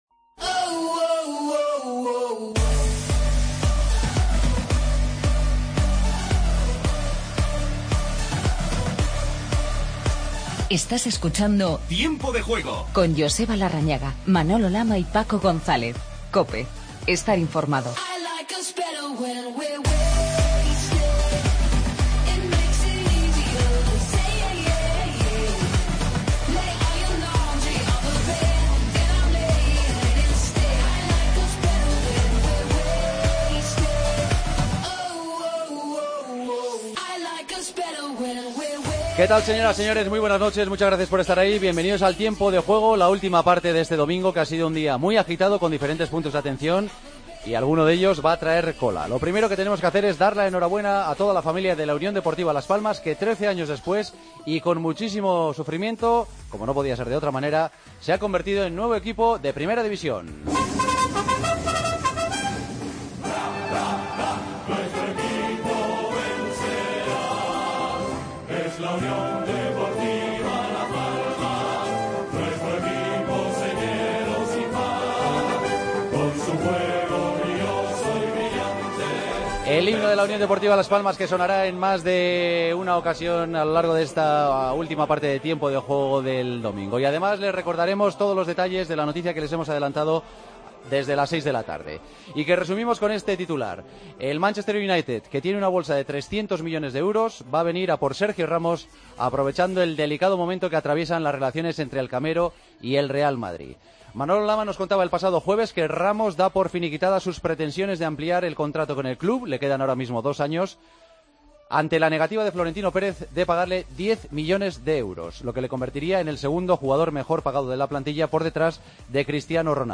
Paso por la ciudad cuyo equipo vuelve a Primera División, Las Palmas de Gran Canaria. Entrevistas a Paco Herrera y Jonathan Viera. El gran damnificado ha sido el Zaragoza.